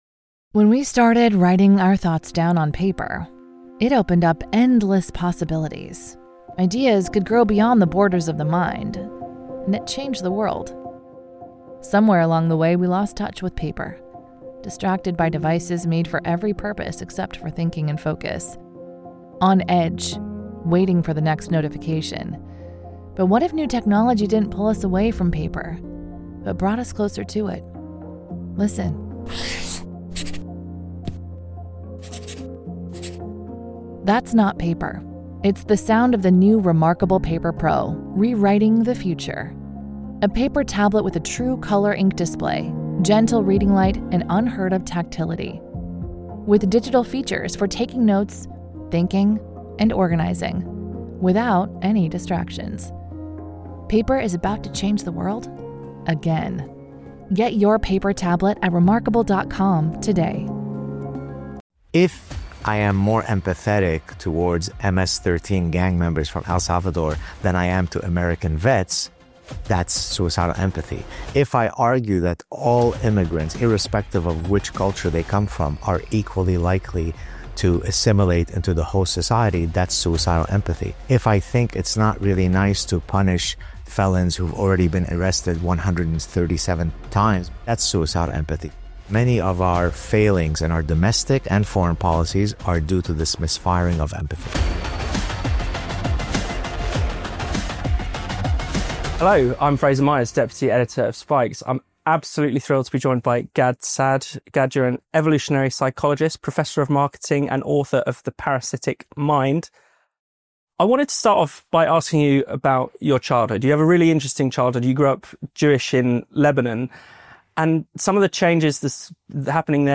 This is the audio from a video we have just published on our YouTube channel – an interview with Gad Saad.